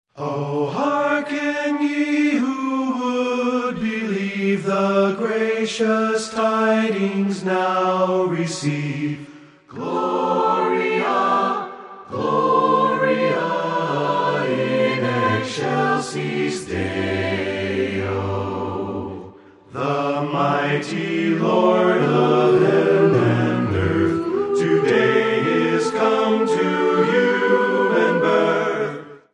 carols in their original form, a cappella.